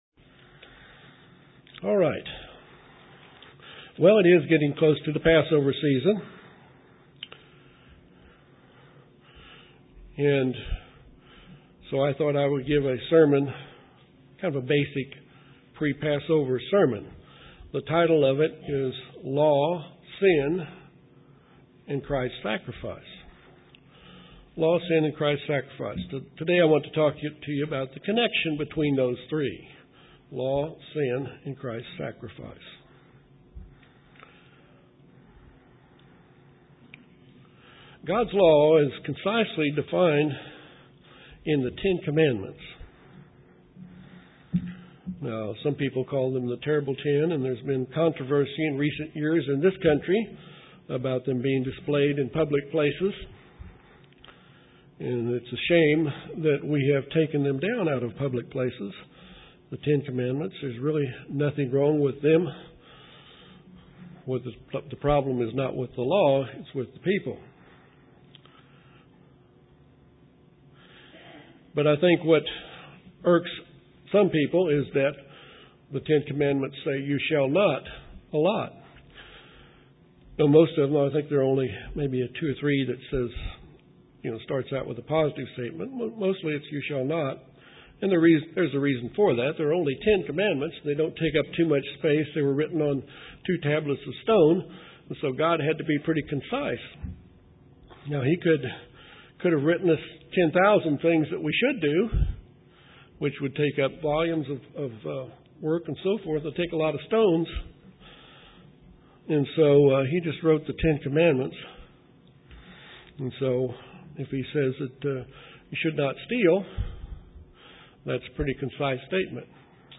This is a pre-Passover sermon. It is a discussion of UCG Statements of Belief, number 5 and number 6.